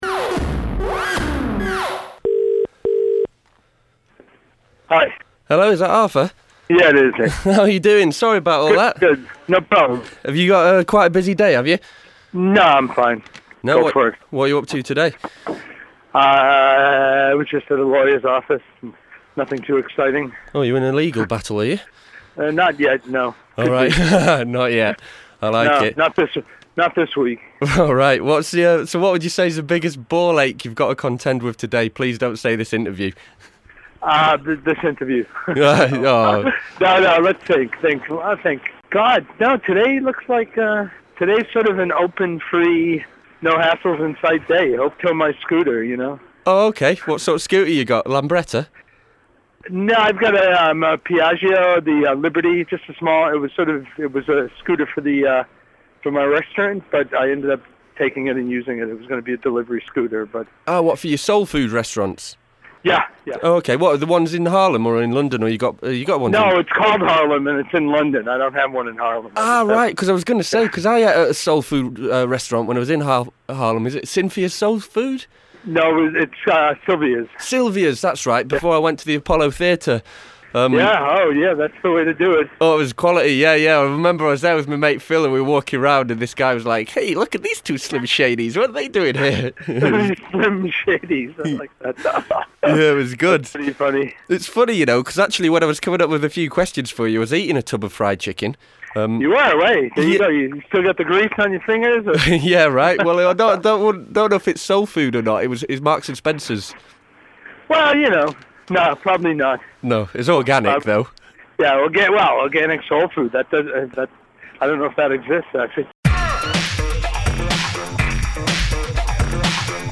Interviews with Sound People... SOUND PEOPLE